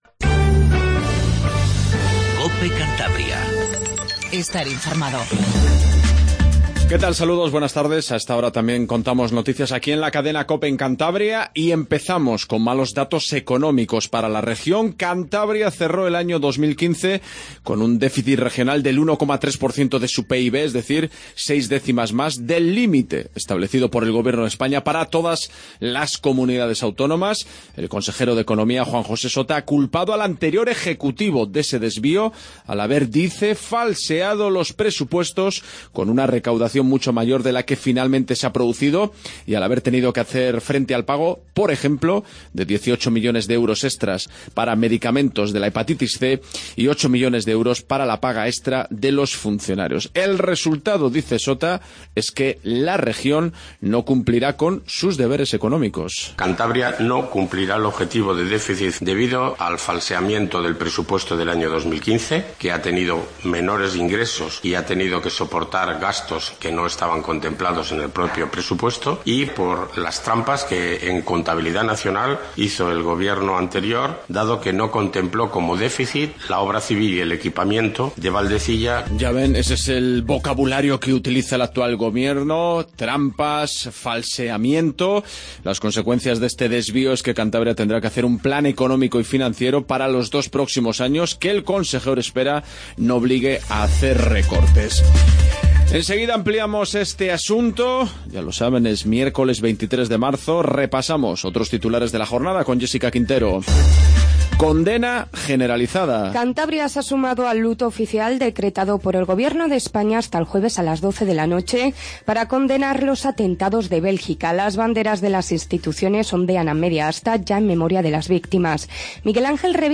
Noticias locales y regionales